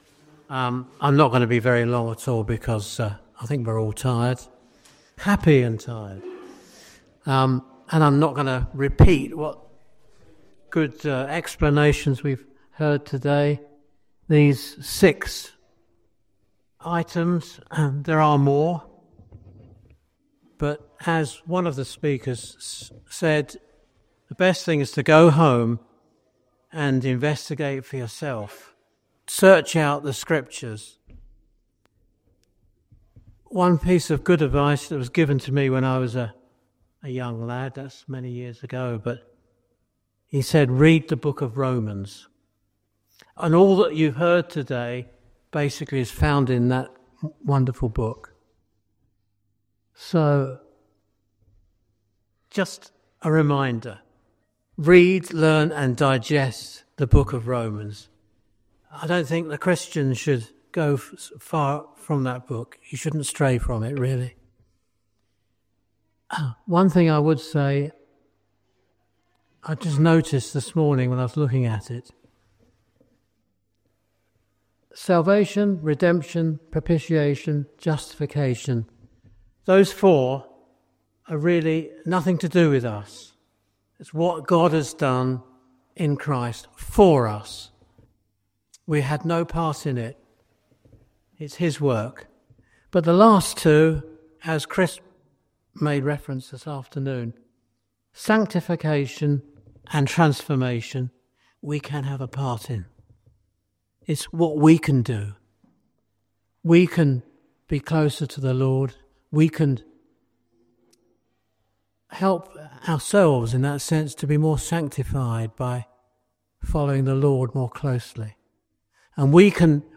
This talk summarised the day’s teachings on Salvation, Redemption, Propitiation, Justification, Sanctification, and Transformation. It reflected on how each topic deepens our understanding of God’s grace, from Christ’s sacrifice for our sins to the ongoing work of being set apart and renewed in Him. The message closed with encouragement to live out these truths daily, trusting in God’s power to transform our lives.